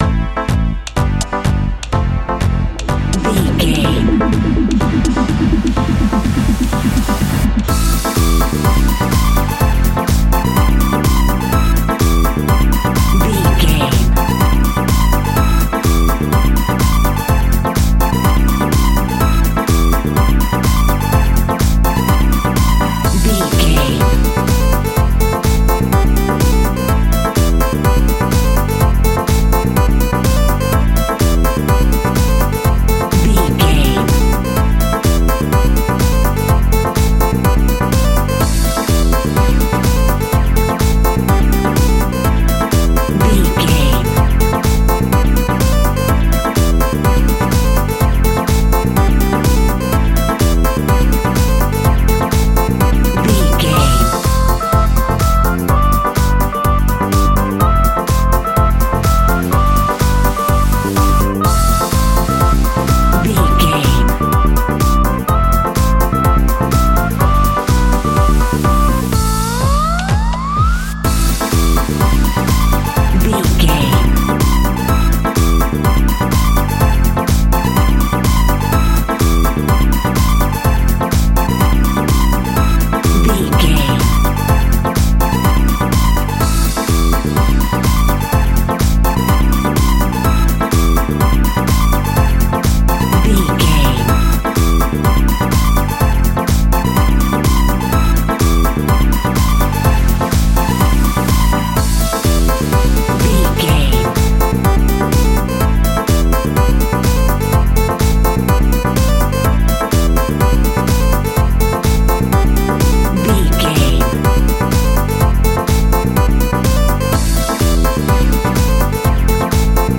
Funk Groove in the Club.
Aeolian/Minor
groovy
smooth
drum machine
synthesiser
bass guitar
funky house
deep house
nu disco
upbeat
funky guitar
wah clavinet
synth bass